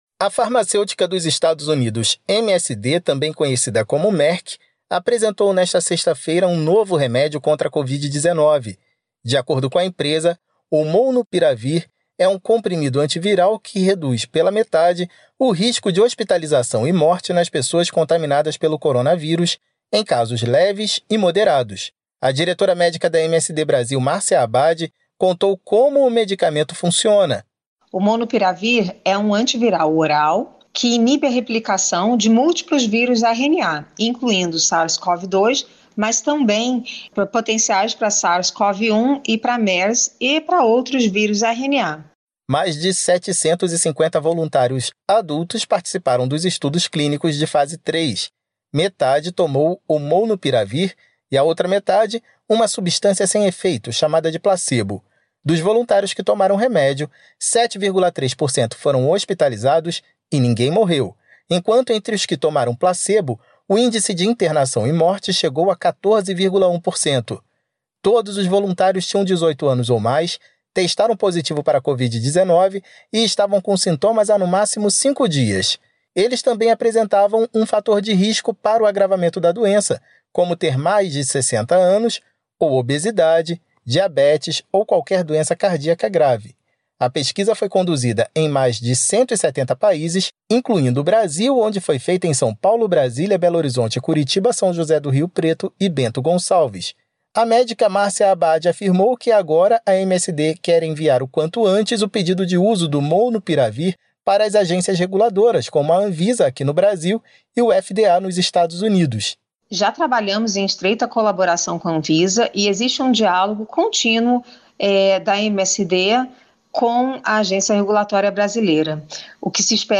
Saúde